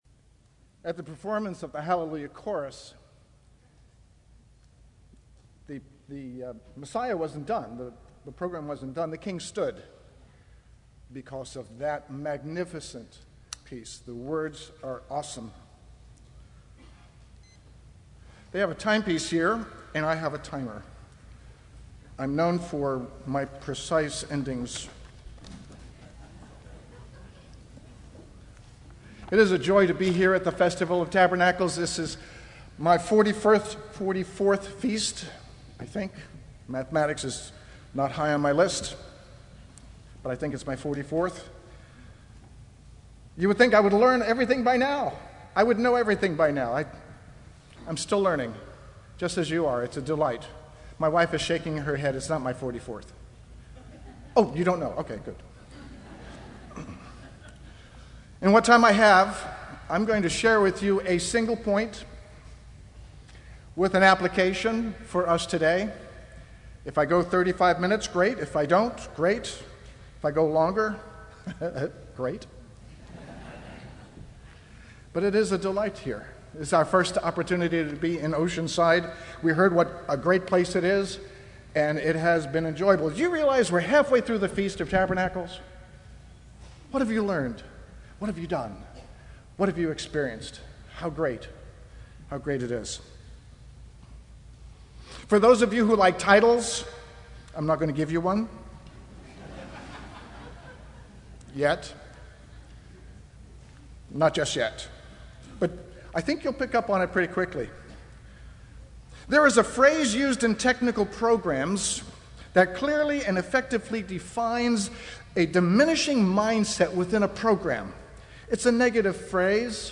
This sermon was given at the Cincinnati, Ohio 2016 and Oceanside, California 2014 Feast sites.
UCG Sermon Feast of Tabernacles Sermon Studying the bible?